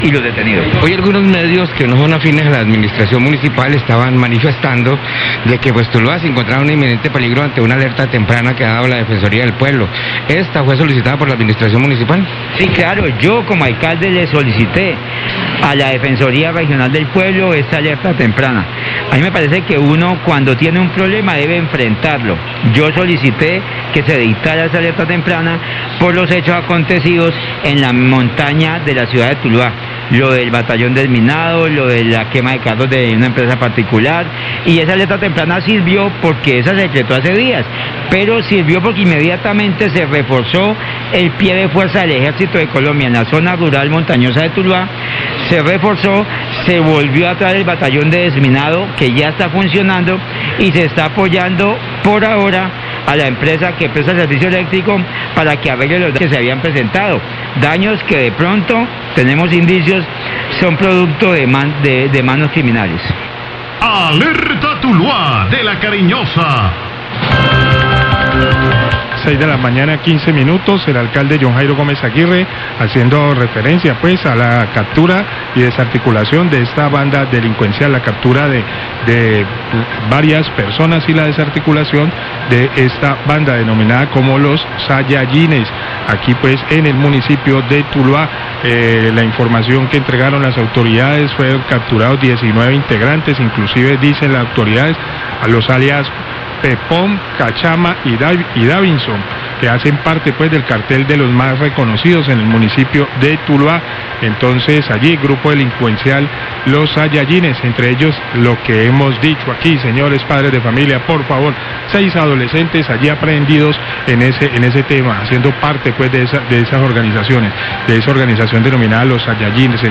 Radio
Alcalde de Tuluá habla de la desarticulación de la banda "Los Sayayines" hace referencia al buen trabajo de las autoridades que se adelanta en la zona urbana y rural, así mismo menciona el regreso del batallón de desminado humanitario a la zona rural y el acompañamiento de las autoridades a la empresa de energía para atender las necesidades de la comunidad.